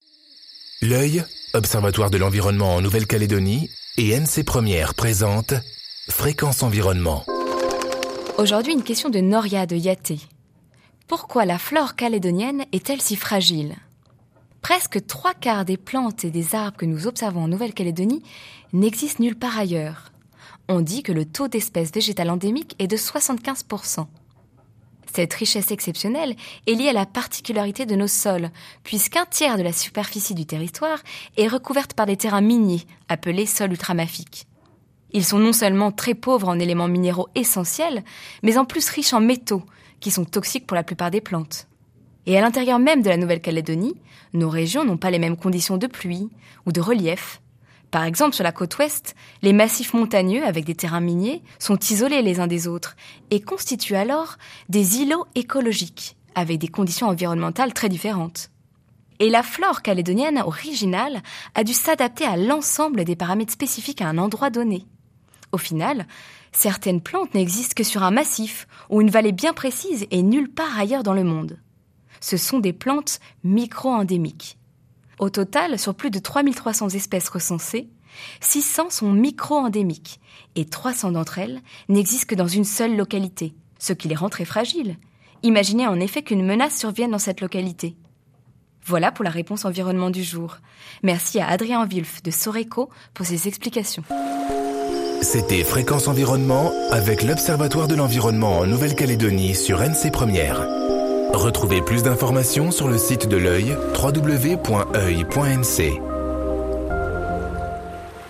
diffusée en décembre 2013 sur NC 1ère